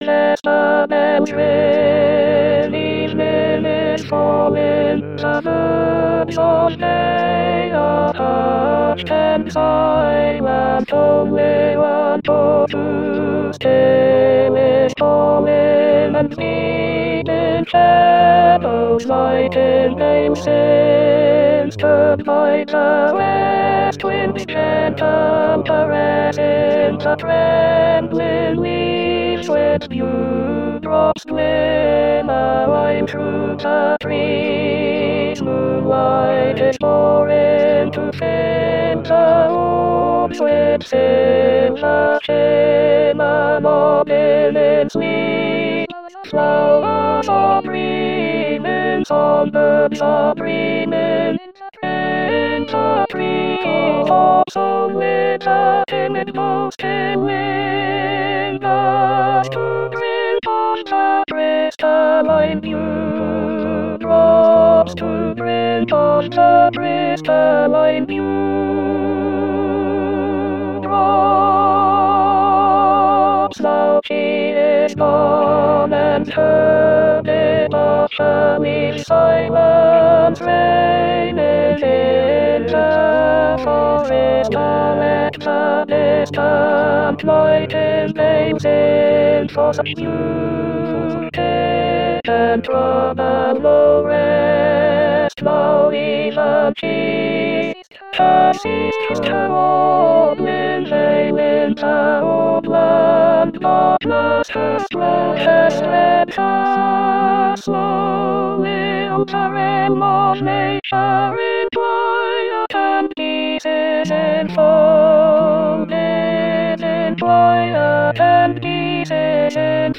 Alto Alto 2